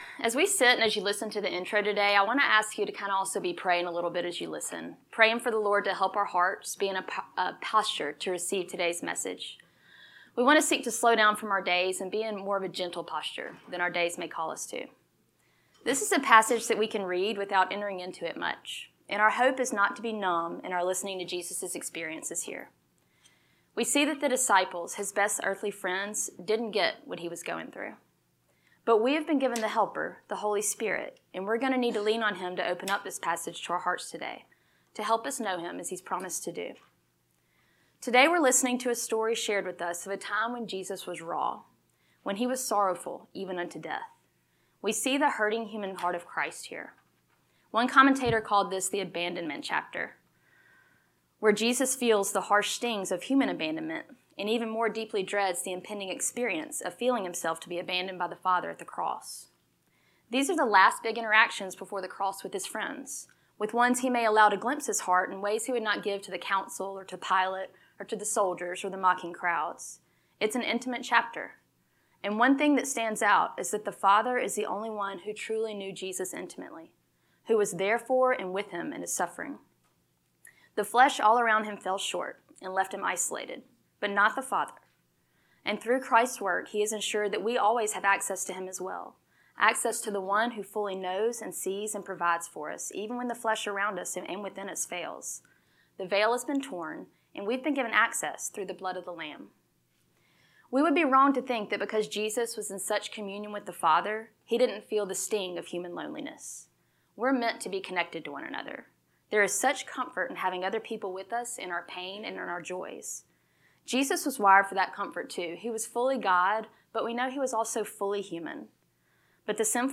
Lesson 20